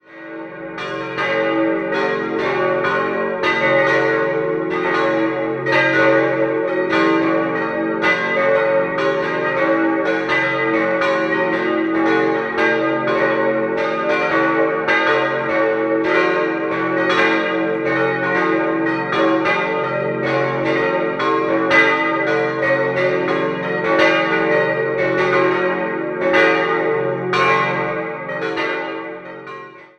4-stimmiges Geläut: des'-es'-ges'-b'